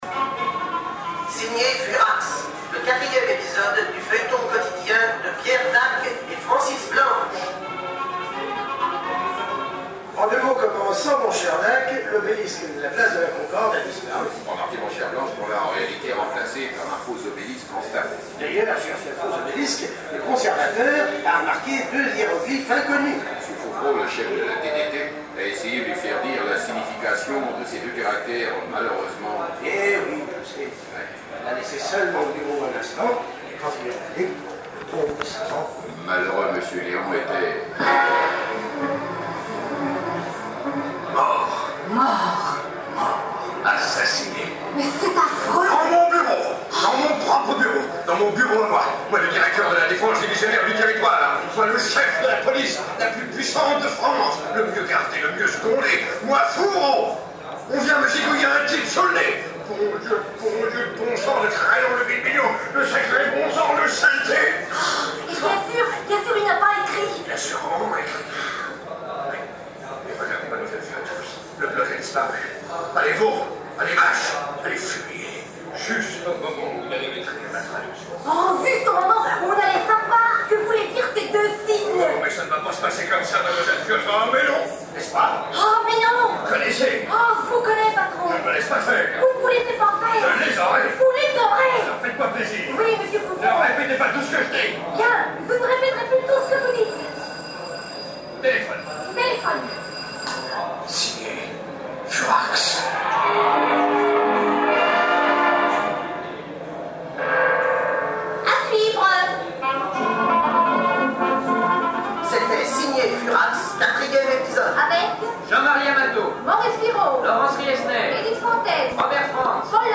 Dans un autre salon, nous avons eu le plaisir de réentendre un épisode du feuilleton « Signé FURAX » et pas le moindre, il s’agit de celui qui a été diffusé le 25 Octobre 1956 à EUROPE 1.